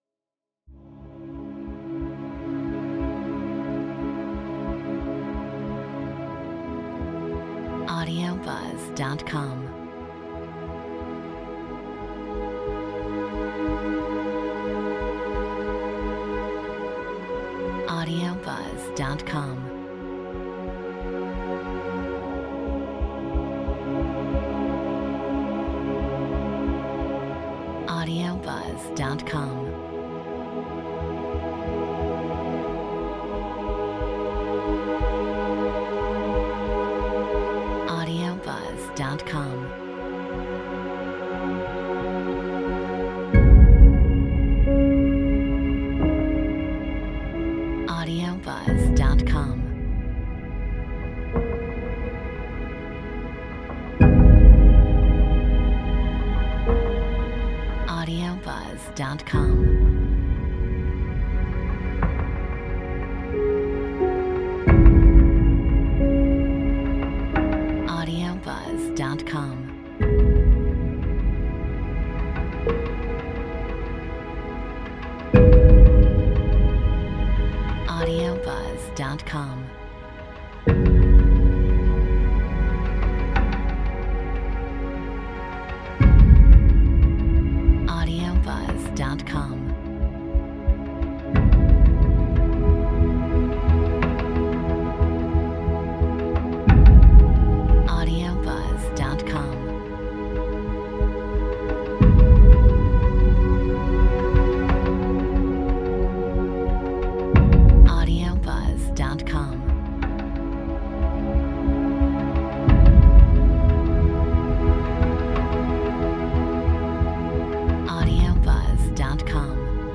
Metronome 90